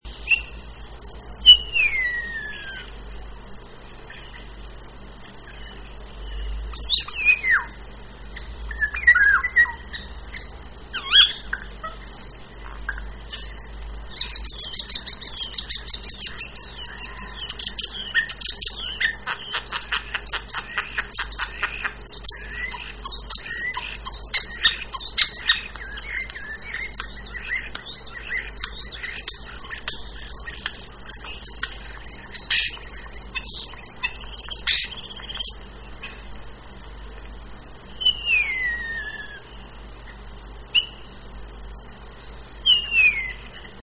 Śpiew szpaków jest bardzo różnorodny, ponieważ potrafią one naśladować głosy innych gatunków ptaków, jak również inne zasłyszane dźwięki.